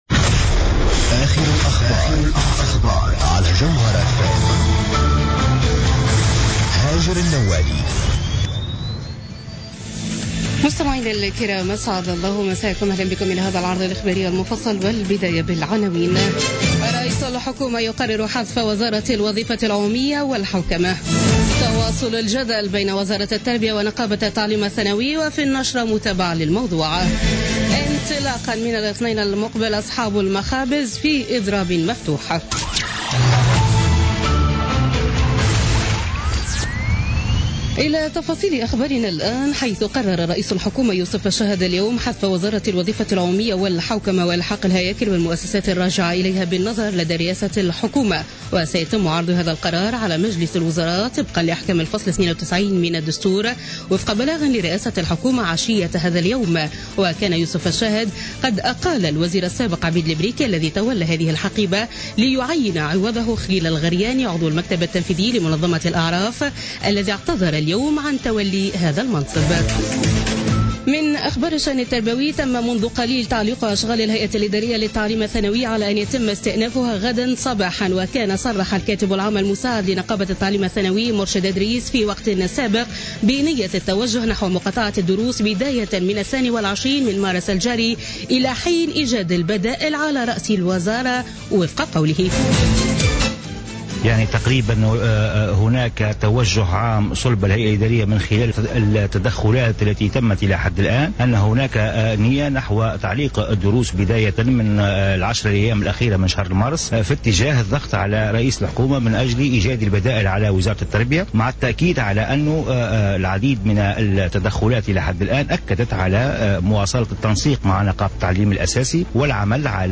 نشرة أخبار السابعة مساء ليوم الخميس 2 مارس 2017